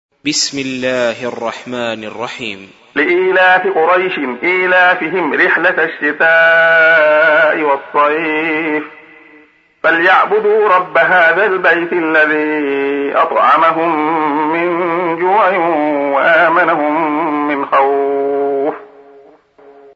سُورَةُ قُرَيۡشٍ بصوت الشيخ عبدالله الخياط